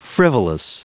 Prononciation du mot frivolous en anglais (fichier audio)
Prononciation du mot : frivolous